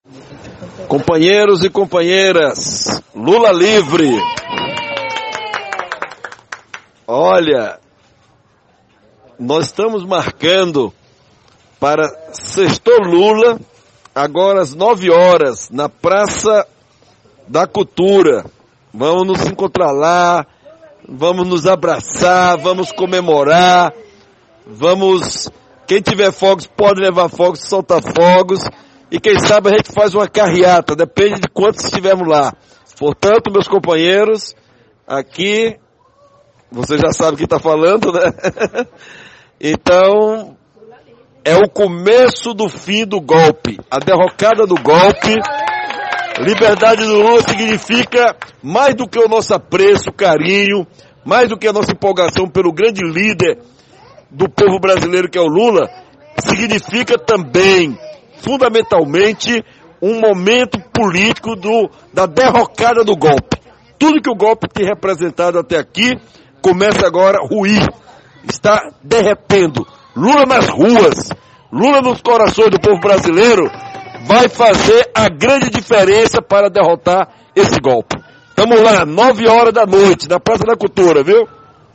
Para comemorar a liberdade de Lula, o prefeito de Quixadá, Ilário Marques (PT) divulgou áudio nas redes sociais convidando os correligionários a irem a praça da Cultura festejar o momento, mas ação foi um fracasso.